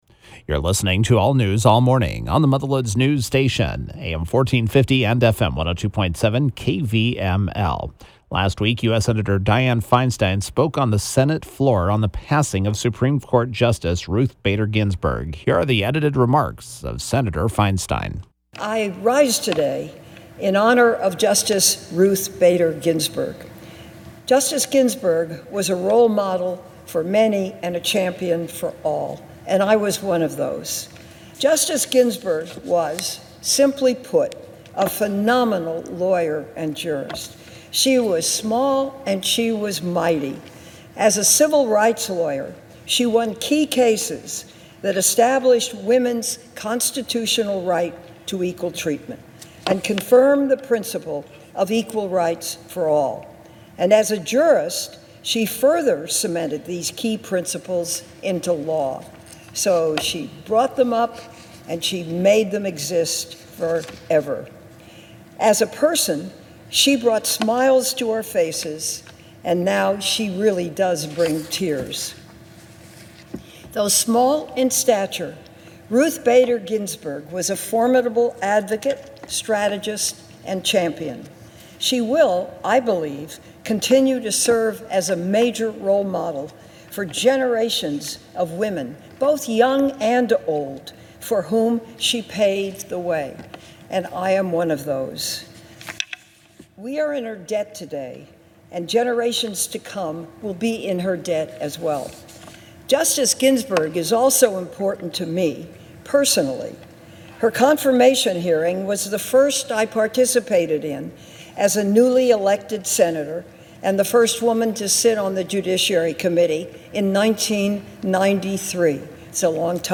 Senator Dianne Feinstein (D-Calif.) spoke on the Senate floor on the passing of Supreme Court Justice Ruth Bader Ginsburg.